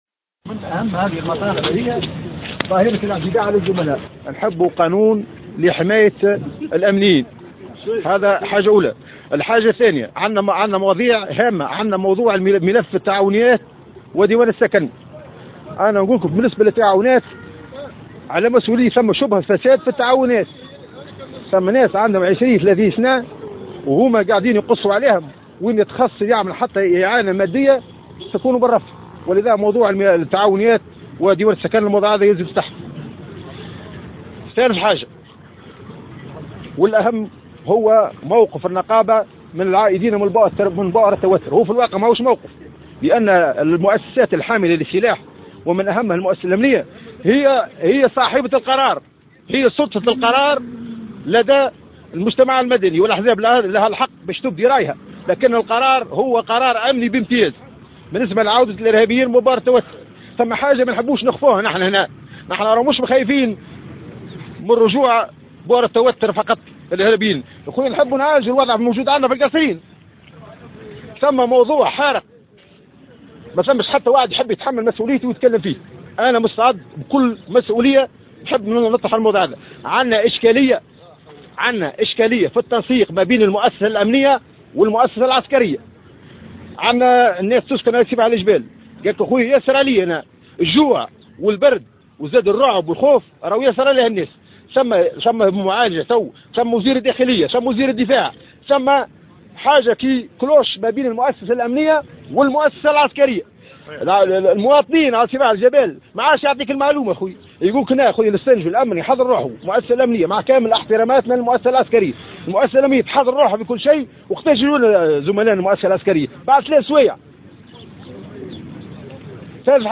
نظّمت صباح اليوم الجمعة النقابة الجهوية لقوات الأمن الداخلي بالقصرين وقفة احتجاجيّة، للتعبير عن رفضها لعودة الإرهابيين من بؤر التوتر.